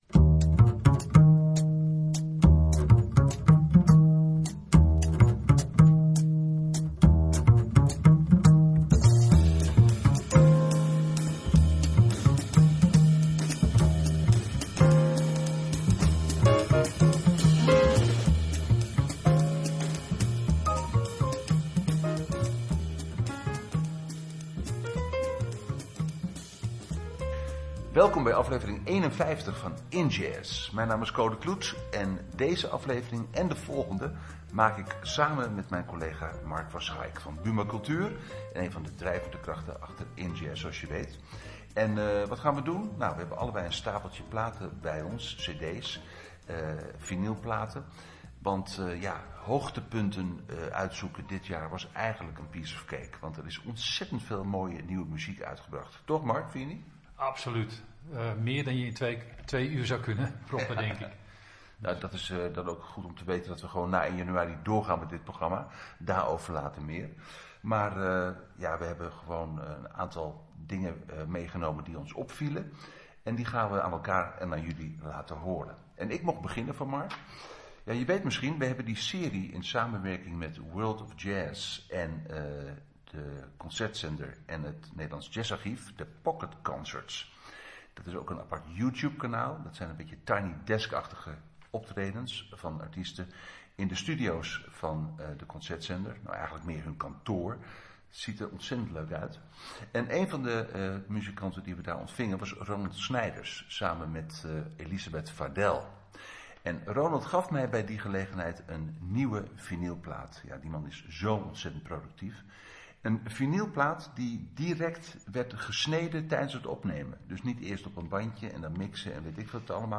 Centraal staat de promotie van jazz en beyond. Een terugblik met de highlights van 2025.